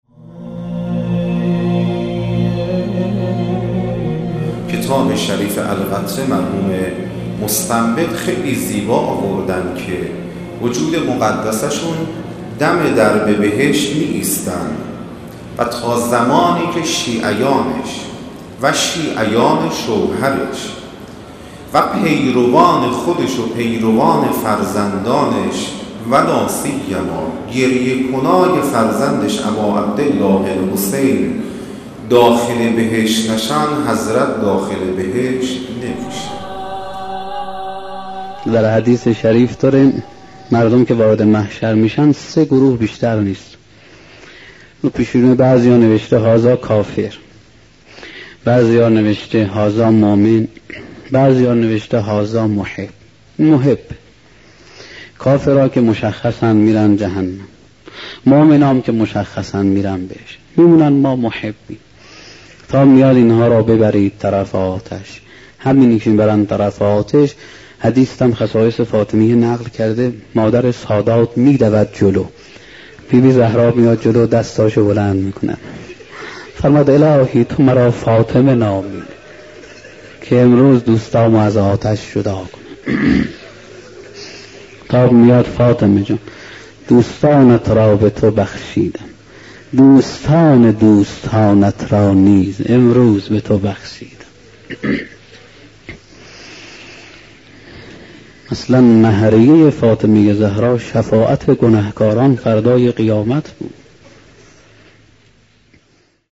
با کلام اساتید بنام اخلاق